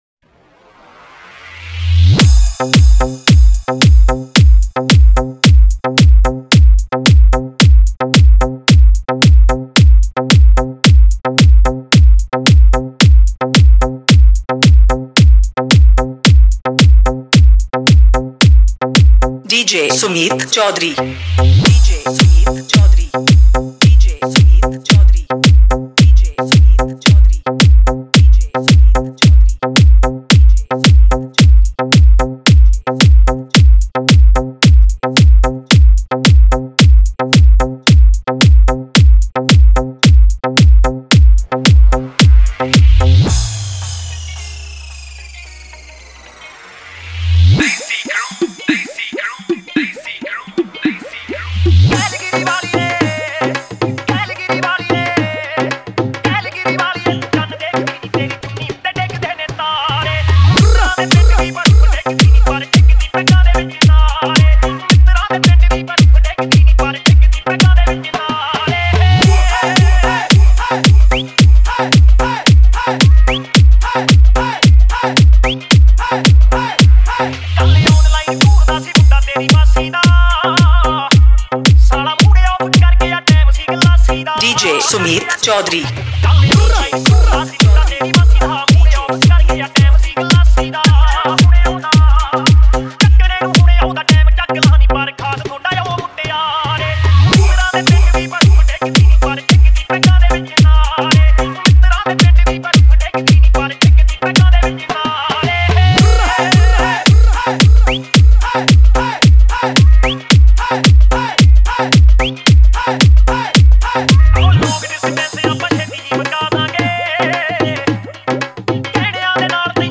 Punjabi Remix Report This File Play Pause Vol + Vol -